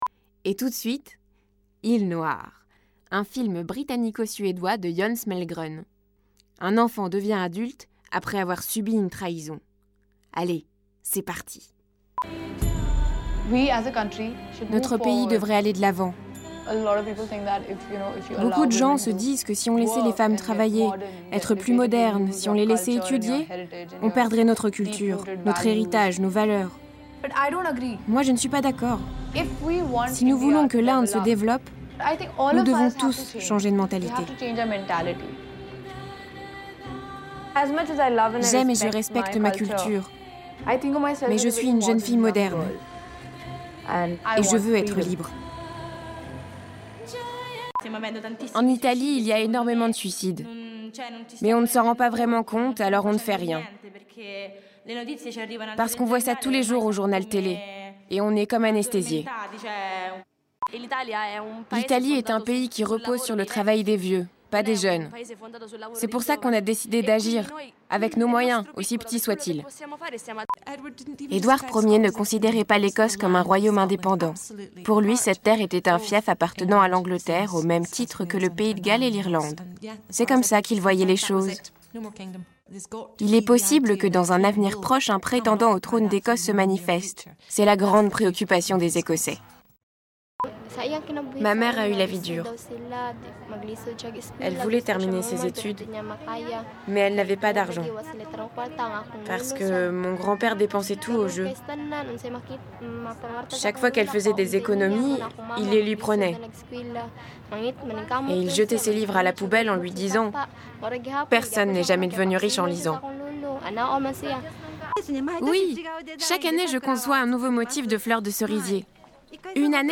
Voix off
Narratrice